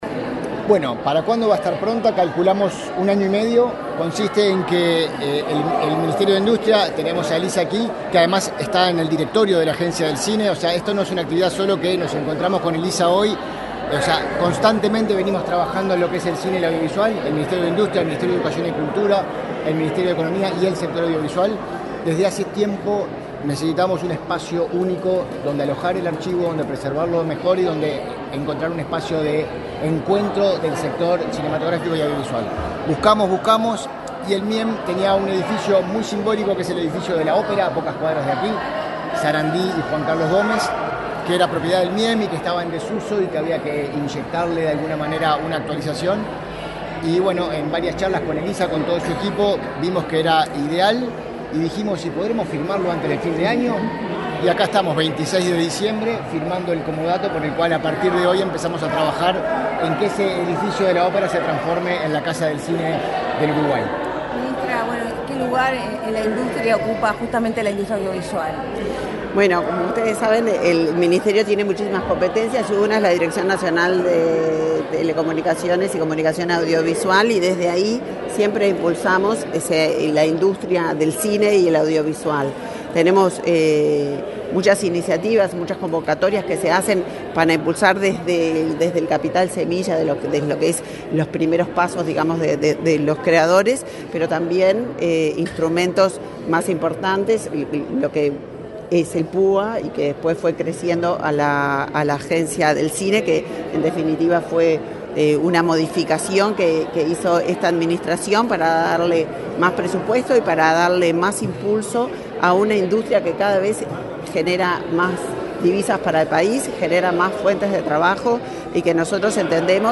Declaraciones de autoridades en la Torre Ejecutiva
El director de la Agencia del Cine y el Audiovisual del Uruguay (ACAU), Facundo Ponce de León, y la ministra de Industria, Elisa Facio, dialogaron con